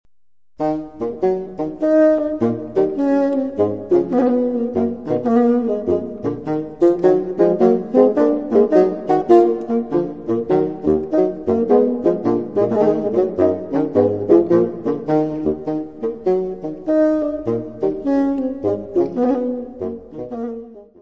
fagote
Music Category/Genre:  Classical Music
Sonate nº5 pour deux bassons en mi mineur
Spiritoso ma largetino.